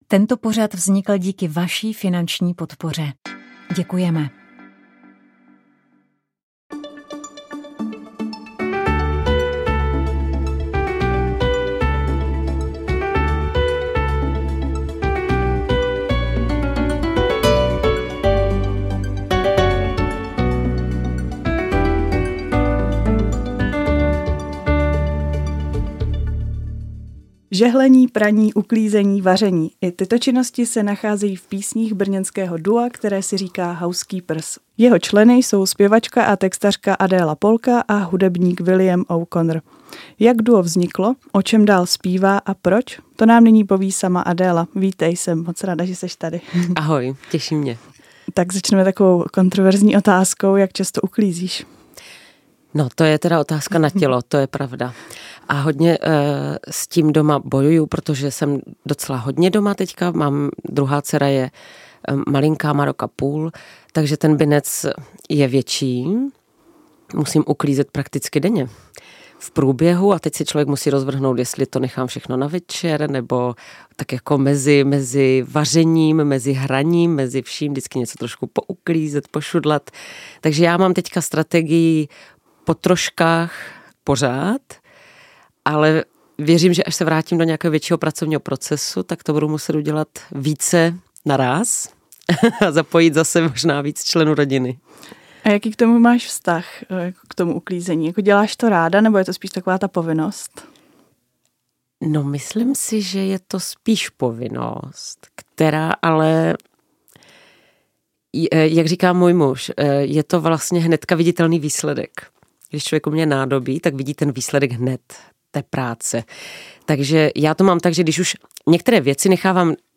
Rozhovor s africkou skupinou Mandé Sila